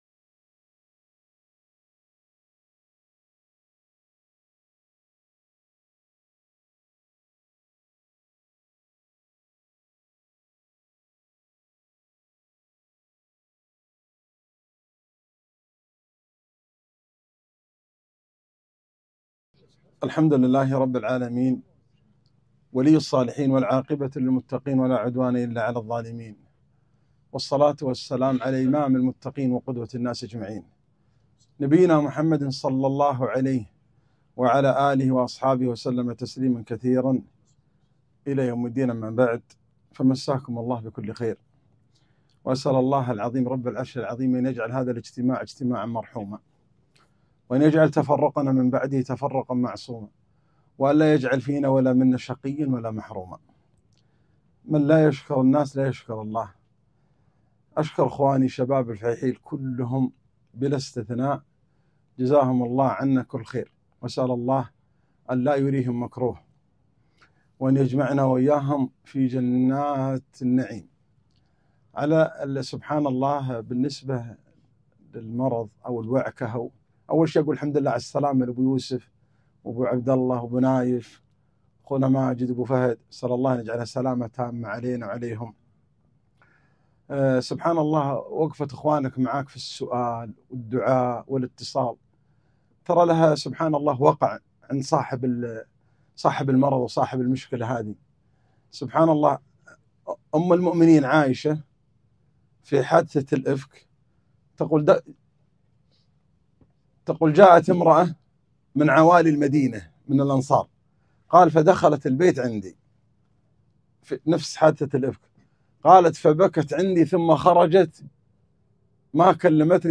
كلمة - من أحبهم أحبه الله